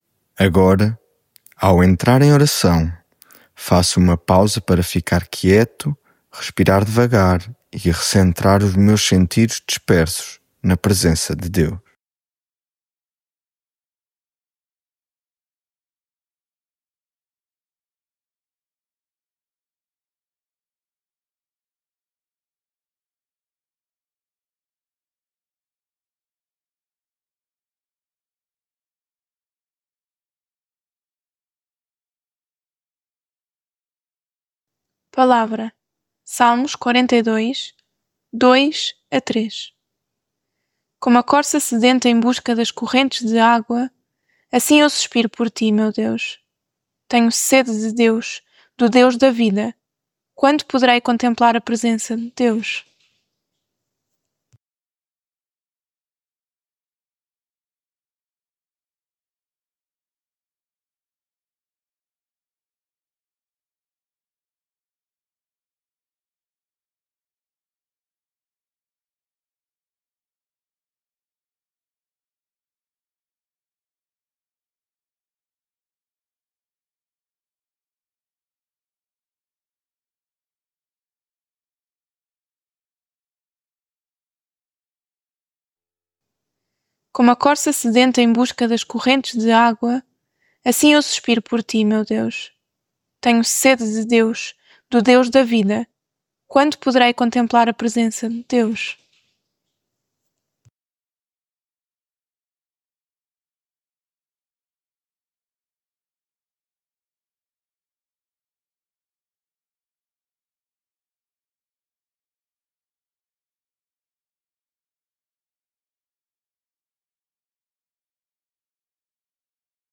Devocional
lectio divina